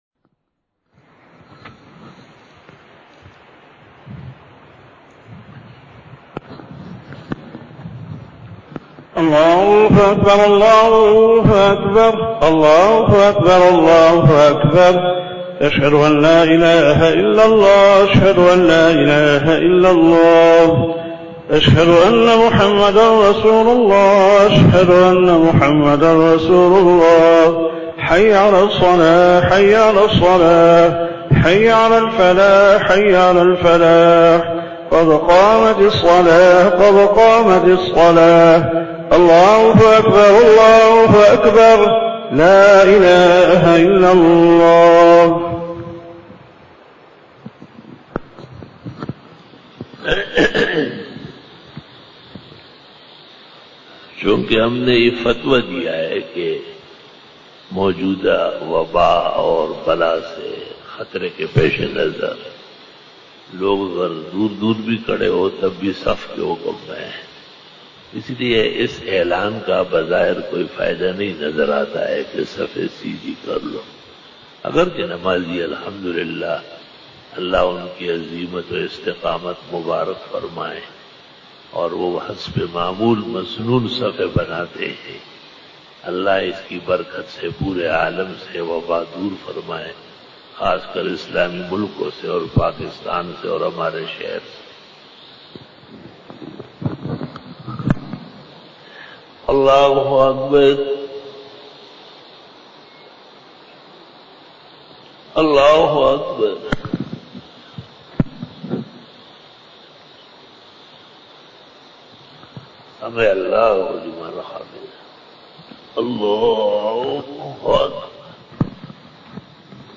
After Namaz Bayan
بیان بعد نماز عصر بروز جمعرات